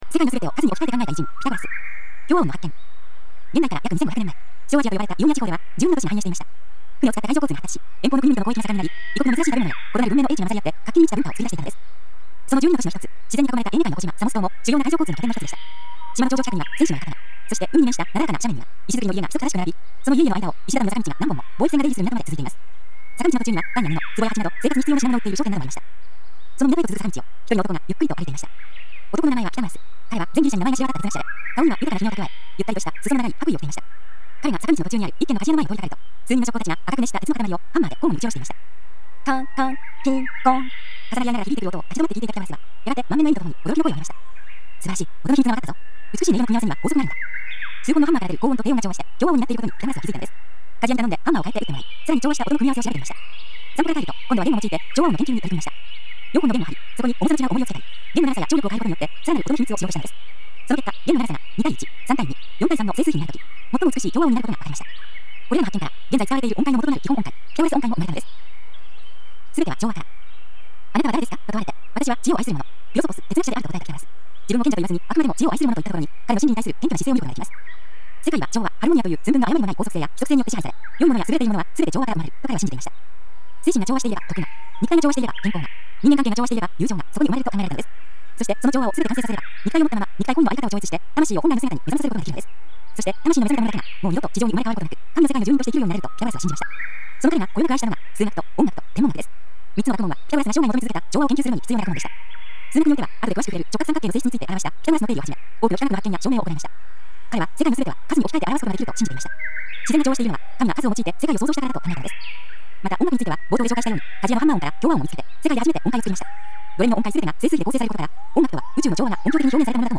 森の中の鳥のさえずりなどの自然音がバックに流れる中、独自のコンテンツや価値ある講演などの音声を、１倍速から無理なく段階的に高速再生し、日々音楽のように楽しく聴くことによって、年齢に関係なく潜在意識を “脳力全開”させていくシステムです
歴史上の偉人たちの話を、わかりやすい文章と穏やかな語りでお伝えする朗読ＣＤです